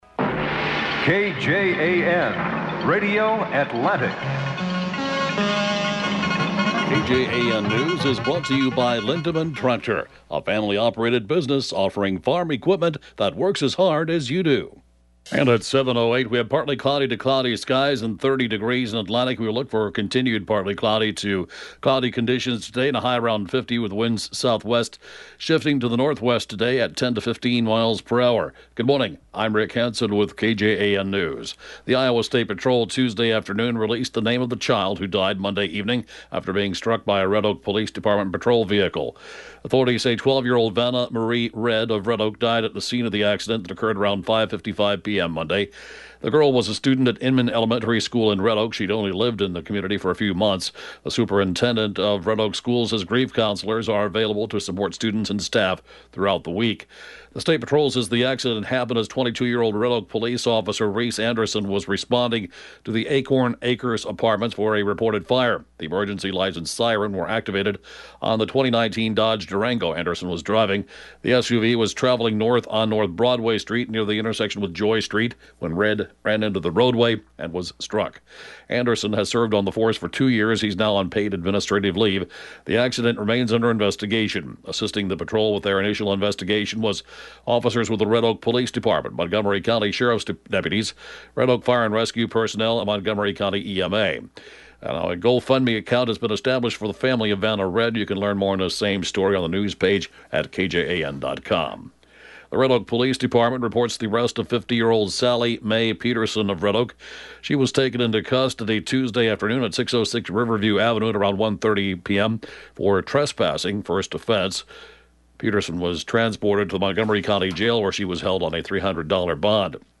(Podcast) KJAN morning News, 1/12/2022